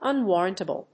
音節un・war・rant・a・ble 発音記号・読み方
/`ʌnwˈɔːrənṭəbl(米国英語)/